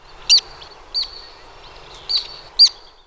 Black Tern
VOICE: The call is a squeaky, "kip."